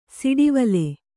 ♪ siḍivale